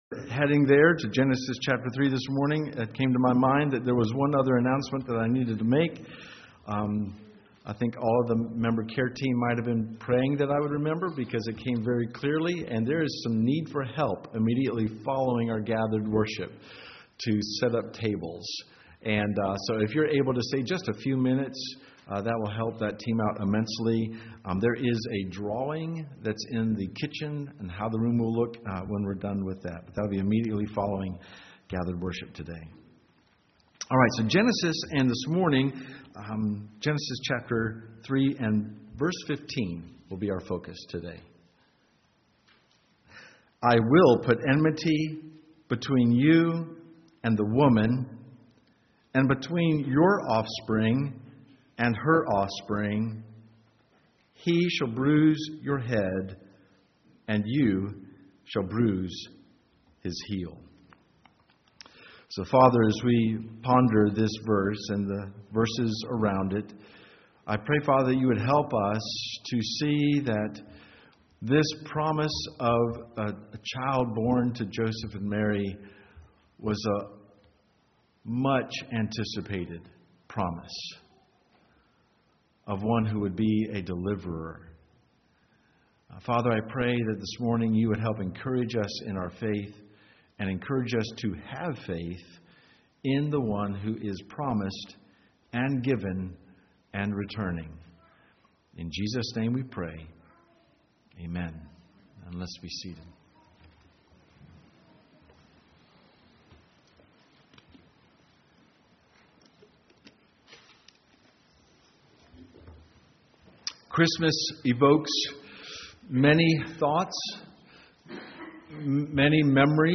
We hope that the Lord will use these sermons to help you in your walk with Him, and help you in glorifying God with all your heart.